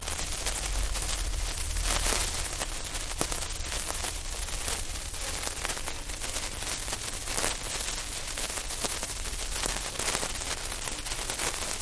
Слушать, как горит бенгальский огонь
• Категория: Огни бенгальские
• Качество: Высокое